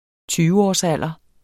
Udtale [ ˈtyːvəɒs- ]